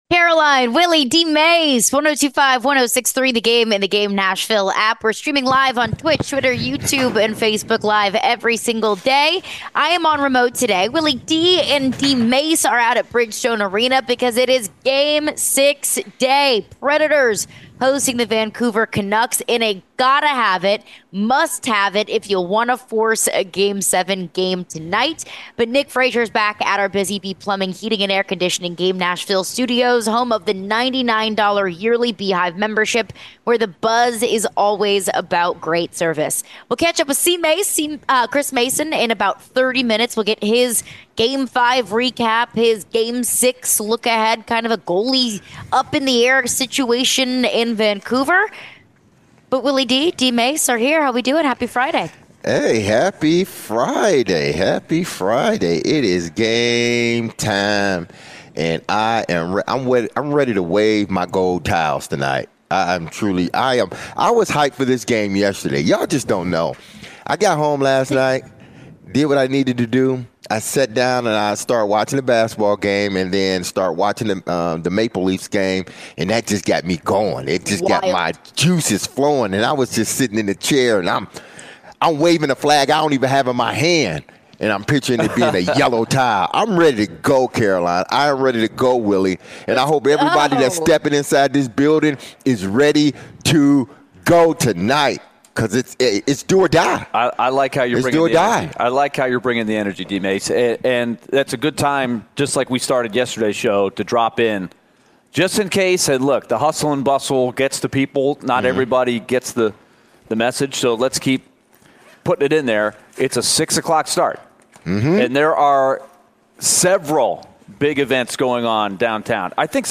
answer some phone calls and texts about the Titans draft.